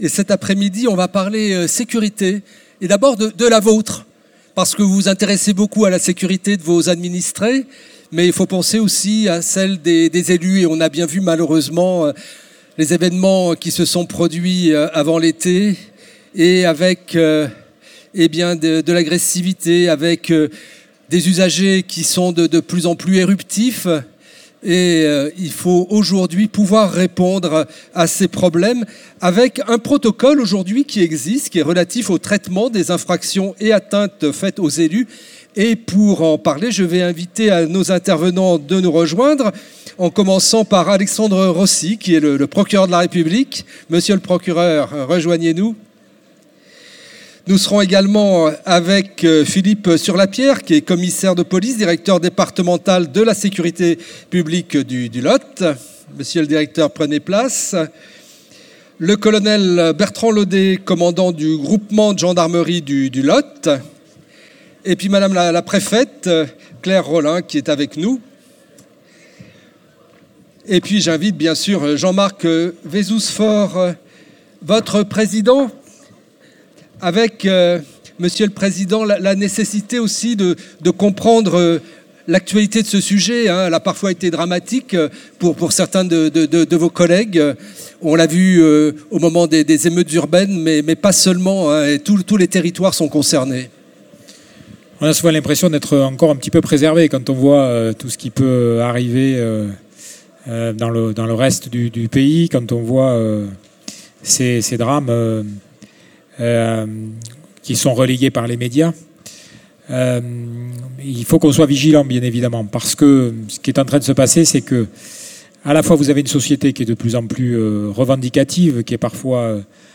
Vous étiez près de 700, le vendredi 20 octobre, au parc des expositions du Grand Cahors à Fontanes, pour l’édition 2023 du Congrès des maires et élus du Lot / 2ème salon des communes et intercommunalités.
Presentation-protocole-infractions-et-atteintes-aux-elus.mp3